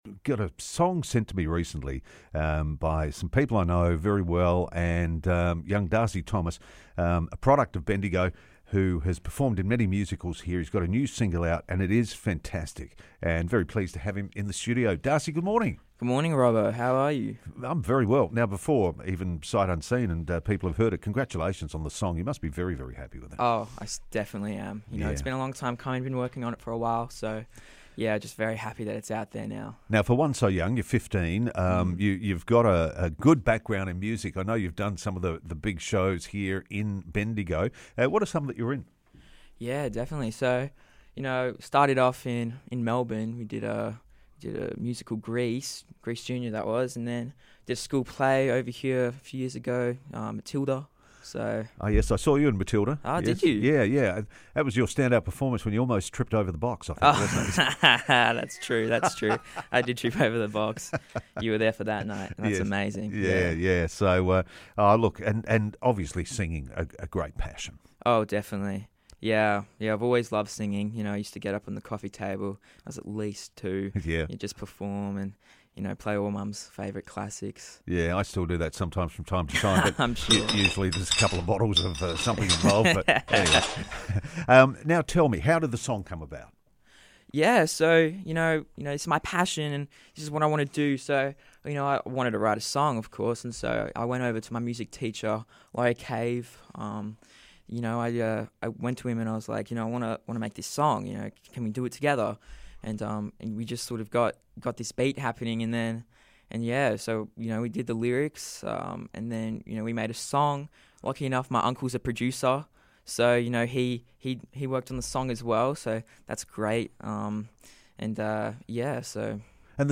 popped into the studio